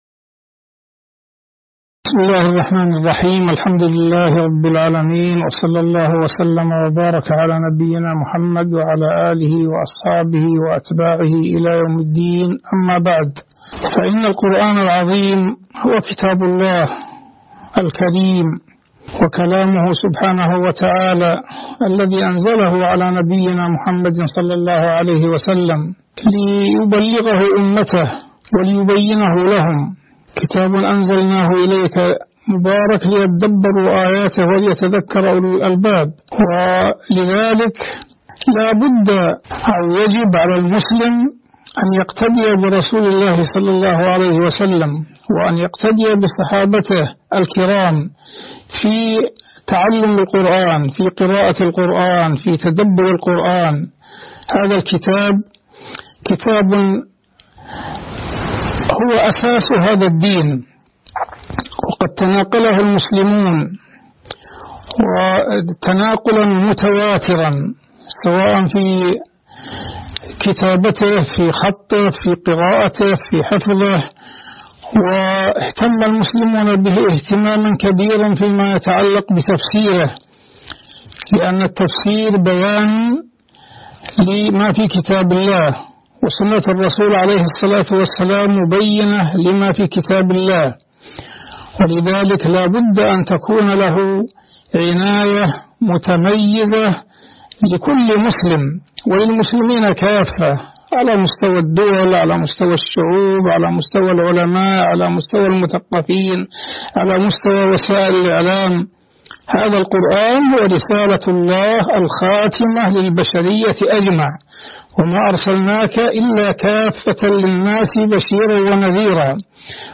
تاريخ النشر ١٦ جمادى الآخرة ١٤٤٣ هـ المكان: المسجد النبوي الشيخ: عبدالله التركي عبدالله التركي القرآن هدى وتبيان